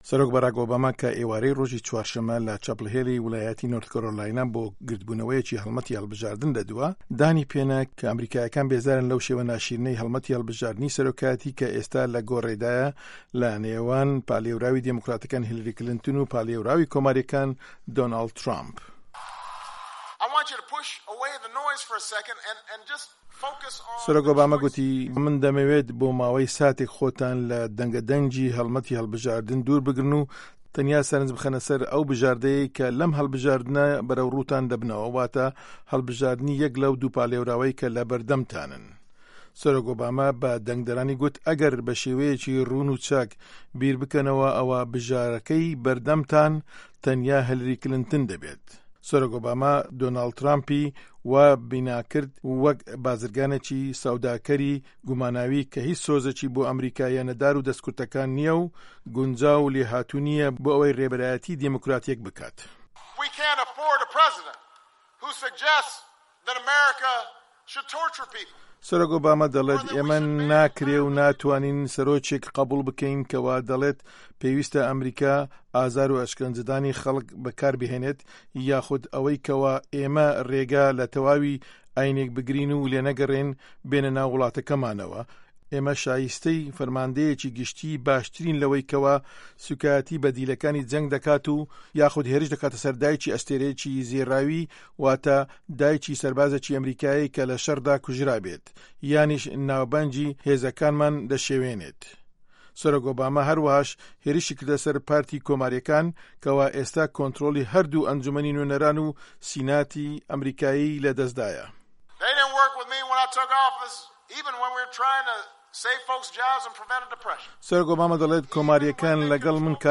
دەقی ڕاپـۆرتەکە